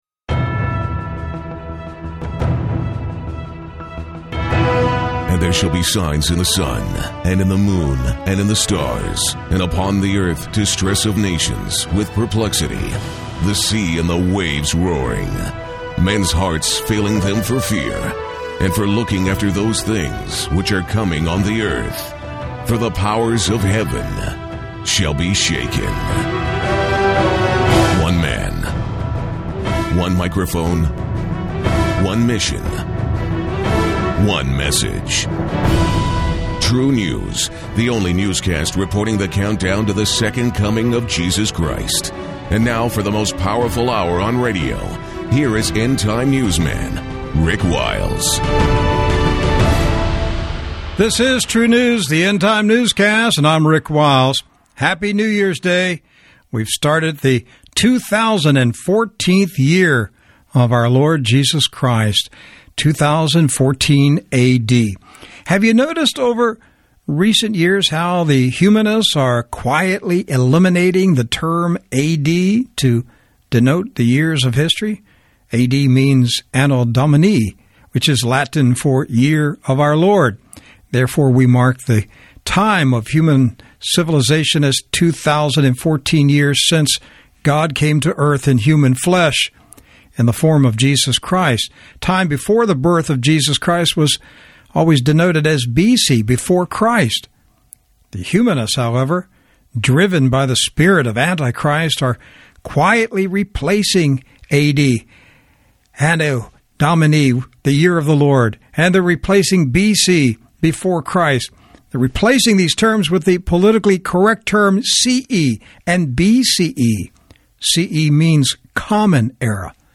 It's not just winter, it's a new ice age . One hour radio program.